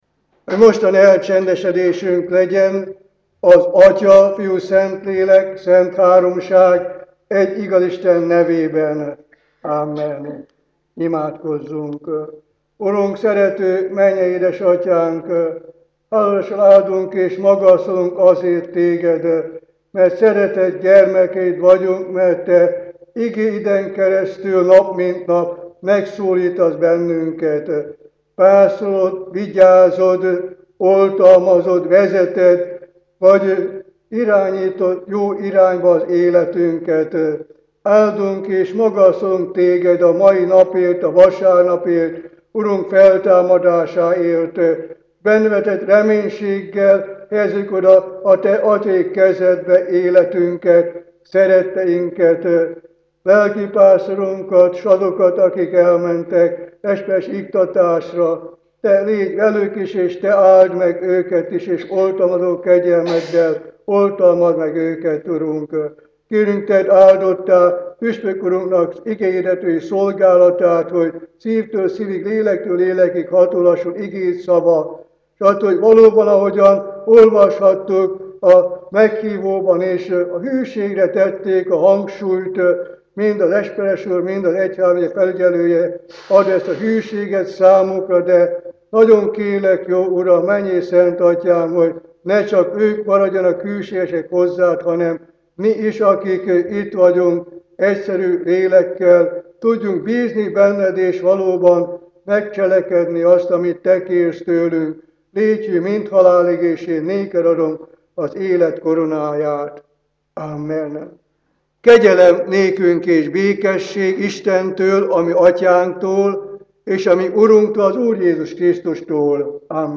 igehirdetése